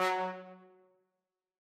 brass.ogg